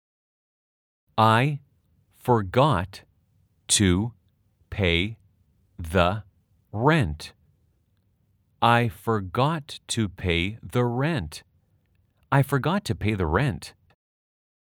/ 아이 퍼갓 투 / 페이더 / 뤠앤트 /
아주 천천히-천천히-빠르게 3회 반복 연습하세요.